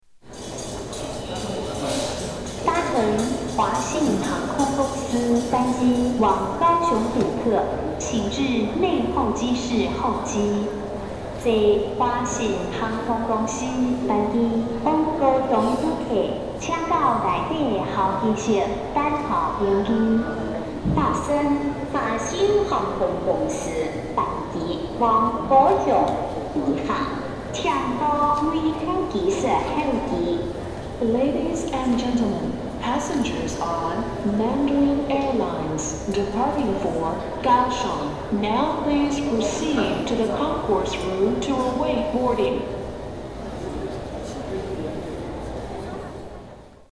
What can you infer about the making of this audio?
International Airport in Taipei